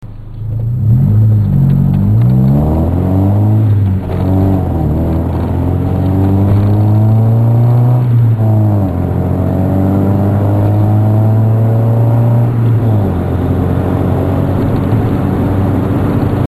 Their only sound byte is the interior sound, so that doesn't tell you anything, it also doesn't list what car its on.
muffler.mp3